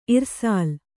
♪ irsāl